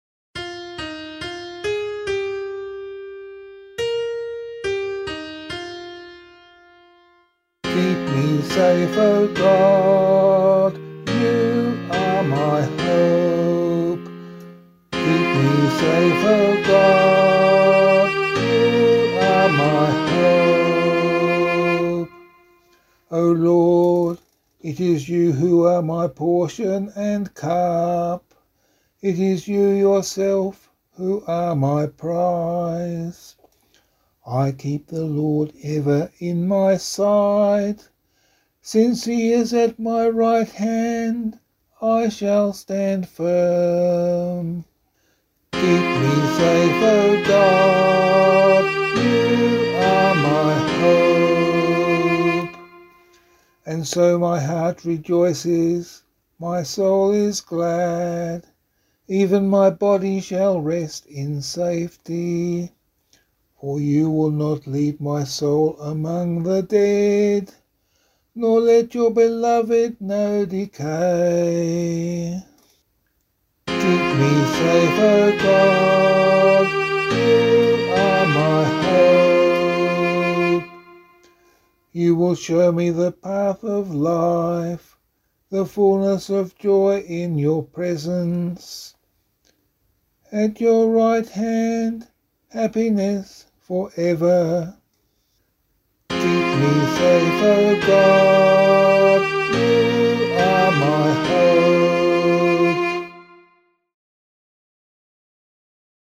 assemblyslidepianovocal